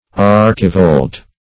archivolt - definition of archivolt - synonyms, pronunciation, spelling from Free Dictionary
Archivolt \Ar"chi*volt\, n. [F. archivolte, fr. It. archivolto;